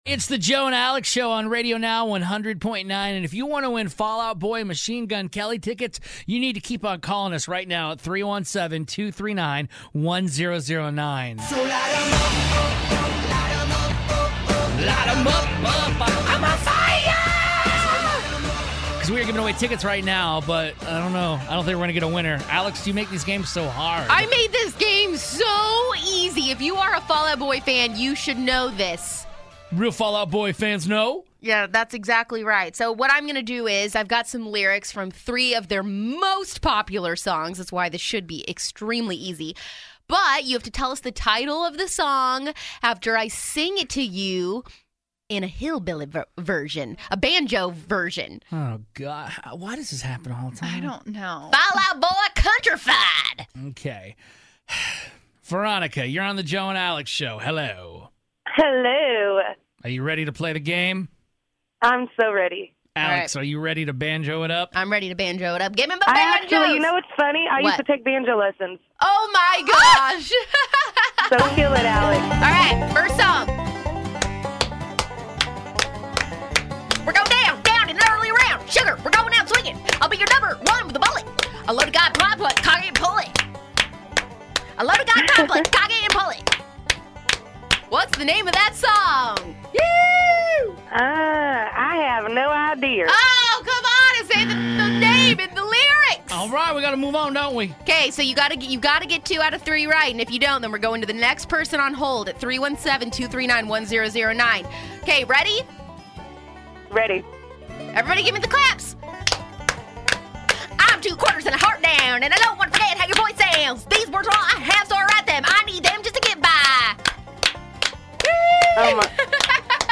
like a hillbilly with banjos in the background